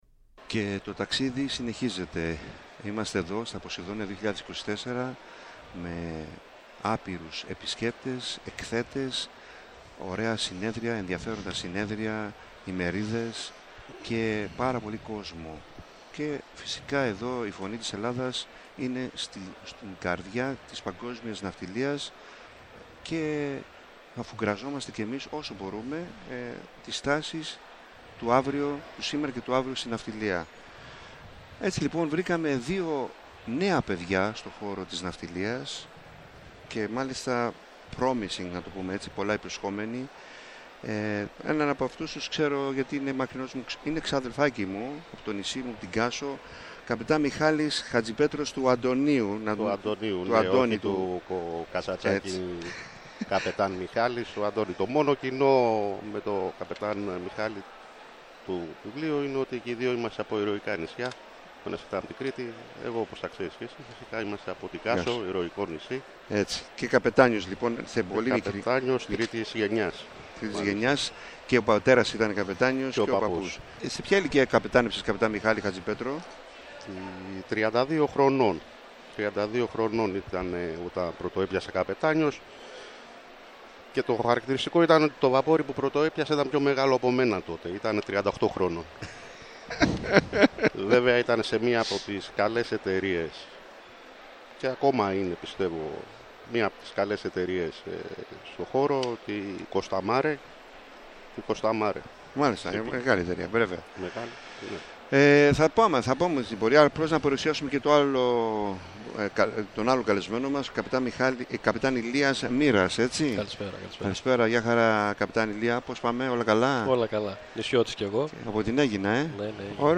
Είχαν περάσει από το περίπτερο – στούντιο της ΦΩΝΗΣ ΤΗΣ ΕΛΛΑΔΑΣ στα ΠΟΣΕΙΔΩΝΙΑ 2024 και μοιράστηκαν μαζί μας σκέψεις, ιδέες και προβληματισμούς τους για τη ναυτιλία, του σήμερα και του αύριο.
Η ΦΩΝΗ ΤΗΣ ΕΛΛΑΔΑΣ Καλες Θαλασσες ΣΥΝΕΝΤΕΥΞΕΙΣ Συνεντεύξεις